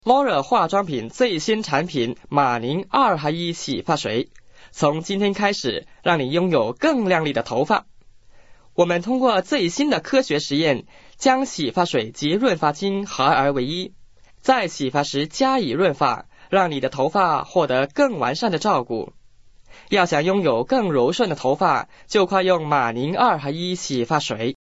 chinesischer Sprecher, mandarin, für Werbung, internet, podcast, e-learning, Dokumentationen uva.
Kein Dialekt
chinese voice over talent